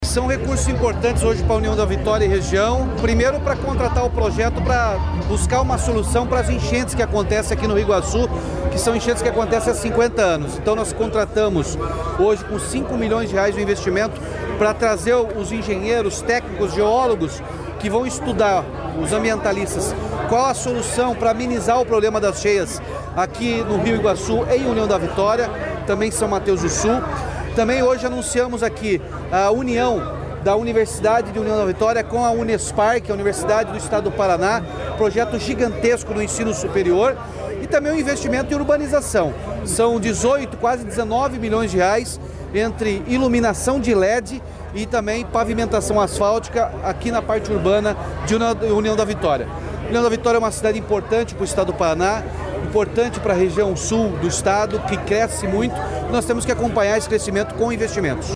Sonora do governador Ratinho Junior sobre os investimentos em União da Vitória